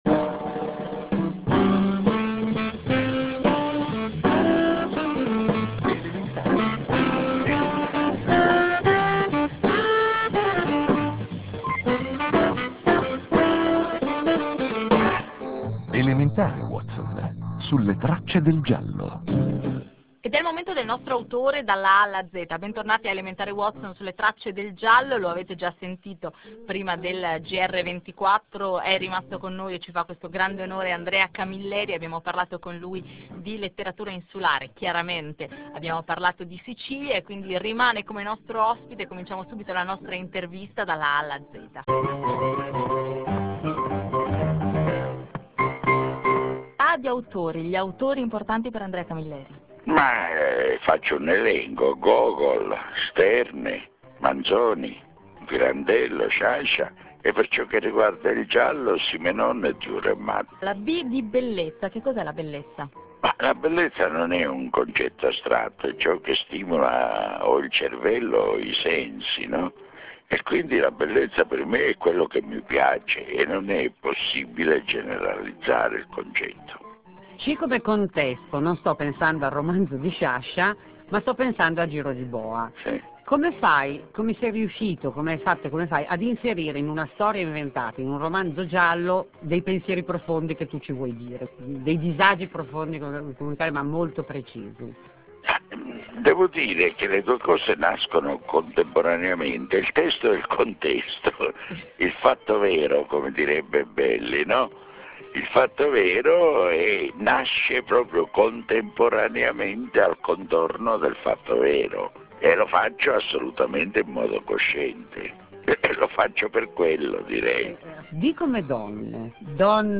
Festa de l'Unità del Vallone 2004, 5.9.2004 Videointervista a Andrea Camilleri In occasione della Festa de l'Unità del Vallone 2004, svoltasi a Mussomeli (CL), Andrea Camilleri ha rilasciato una videointervista in esclusiva messa in onda il 5 settembre 2004.